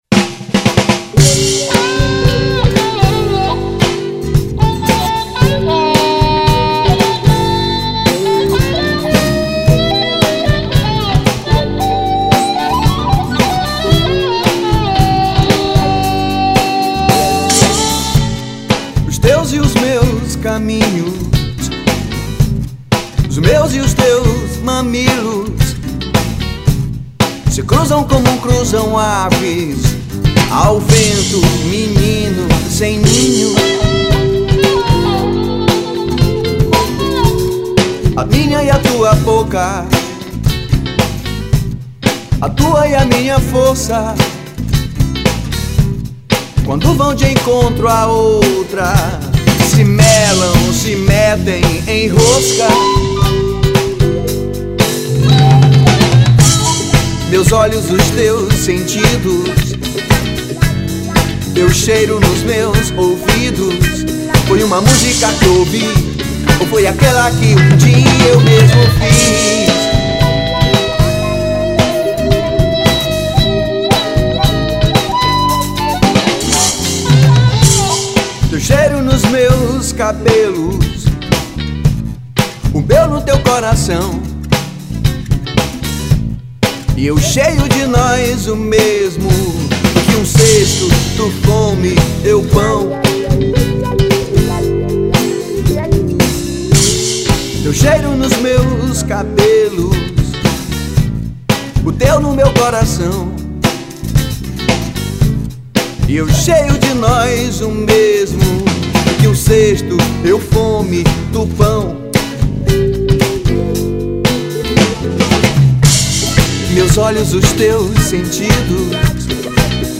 1982   02:50:00   Faixa:     Rock Nacional